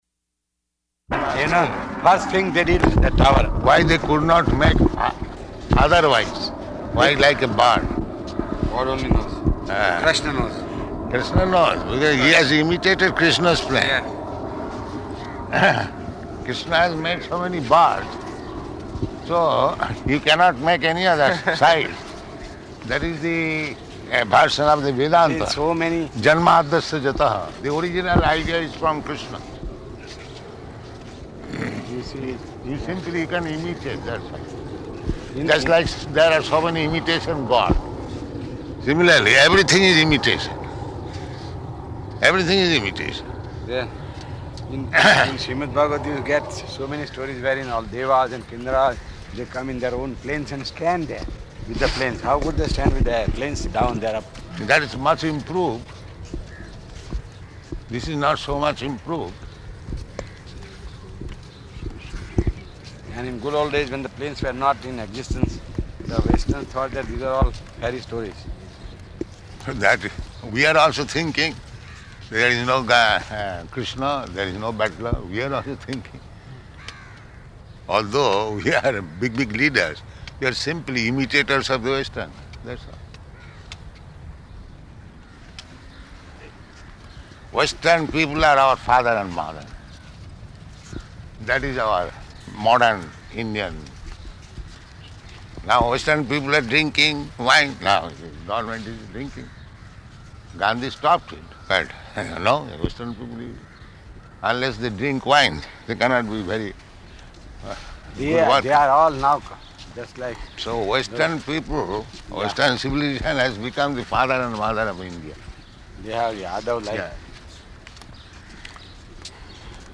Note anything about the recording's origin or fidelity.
March 27th 1974 Location: Bombay Audio file